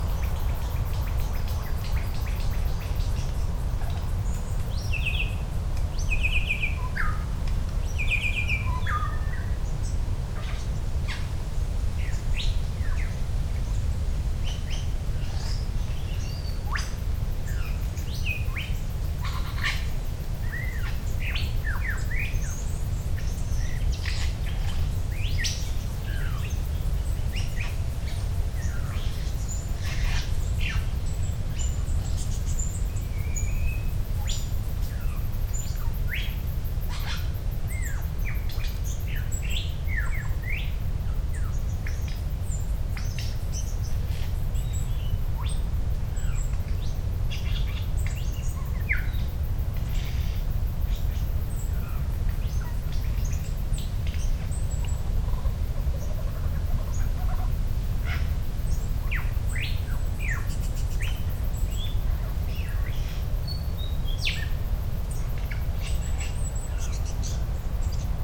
Walking near Cape Conran I came upon a family of lyrebirds, seemingly a mother and three offspring.
Her singing included distinctive calls, which sounded like two oscillators frequency modulating one another, but also the sounds of many other birds. In a short time we heard the calls of whip birds, wattle birds, black cockatoos, and even kookaburras amongst others.
lyrebirds.mp3